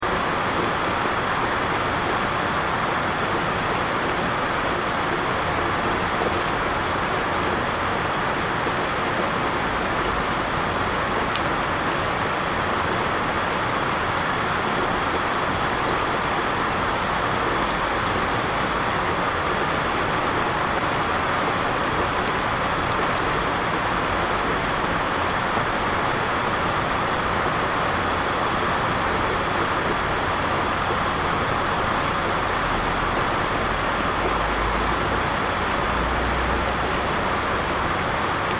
دانلود آهنگ باران 4 از افکت صوتی طبیعت و محیط
جلوه های صوتی
دانلود صدای باران 4 از ساعد نیوز با لینک مستقیم و کیفیت بالا